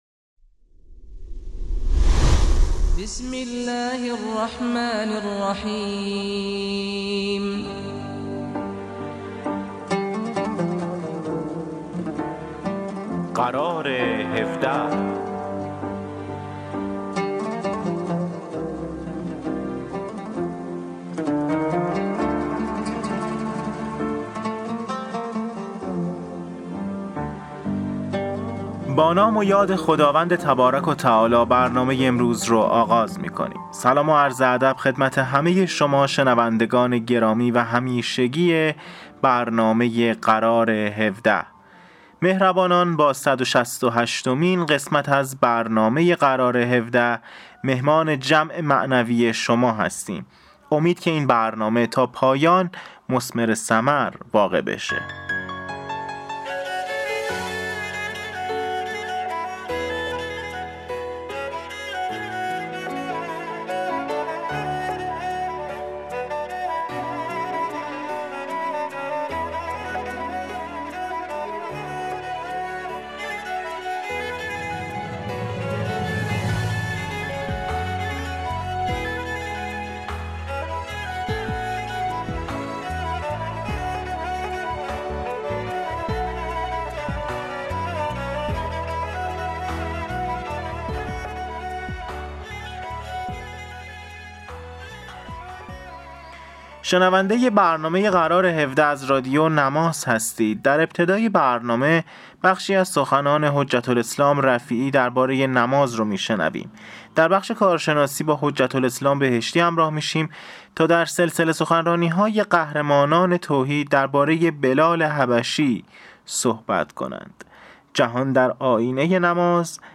قسمت صد و شصت و هشتم رادیو نماز – مجله رادیویی قرار هفده